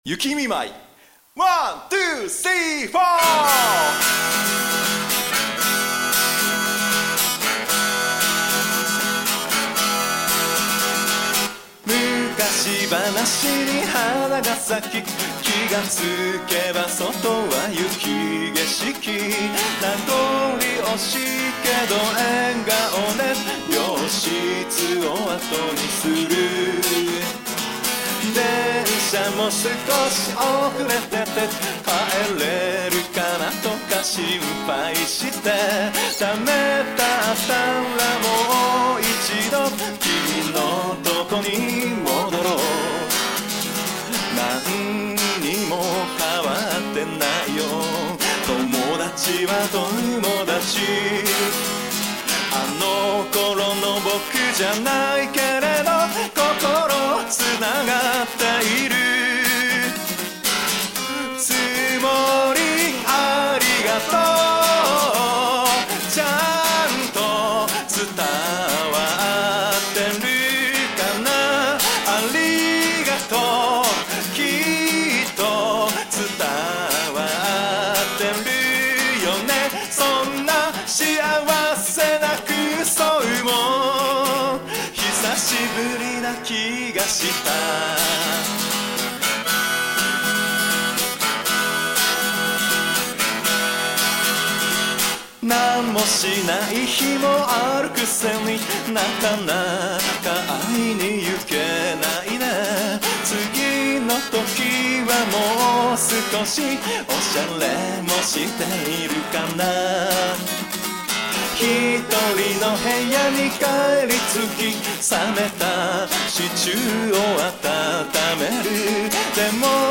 Memo : 最後はギター弾き語り。